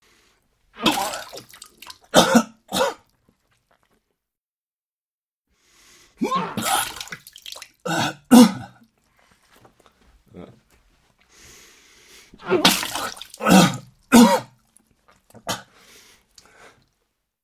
Звуки рвоты, тошноты
Звук рвоты человека